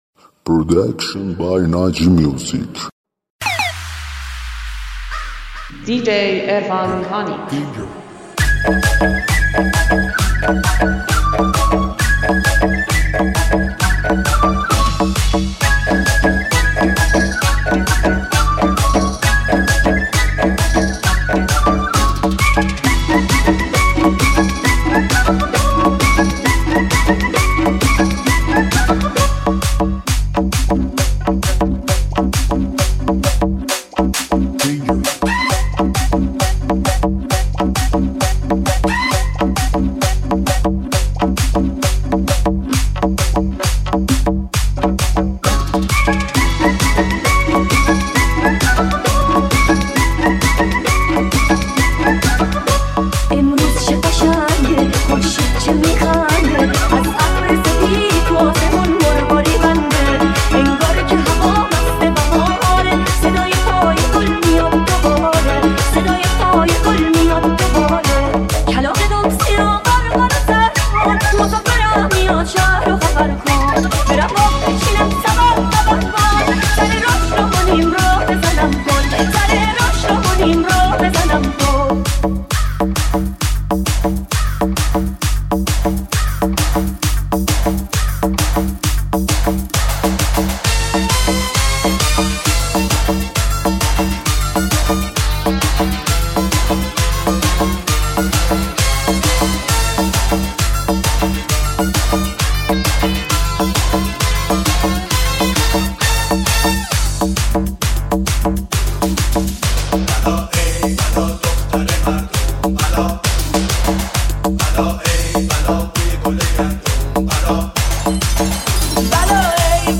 رمیکس نوستالژیک دهه 60
ریمیکس شاد تریبال قر کمری